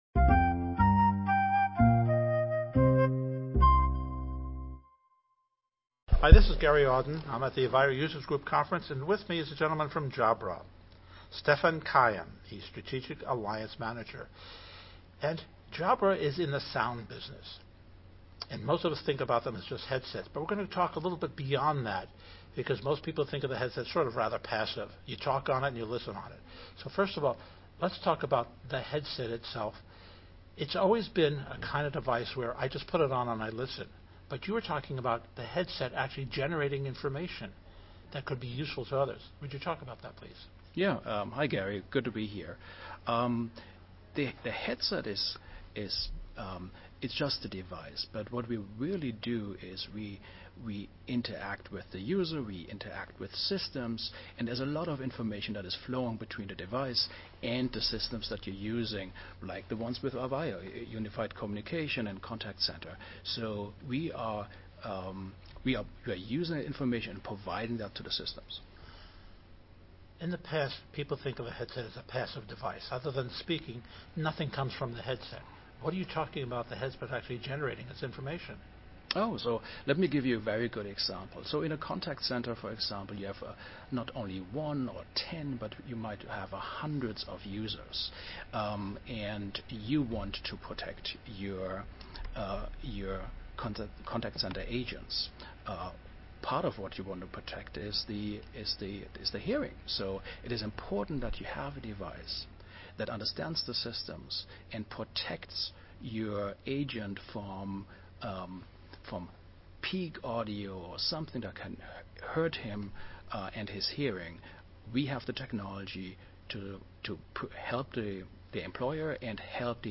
In the following podcast at the International Avaya User Group conference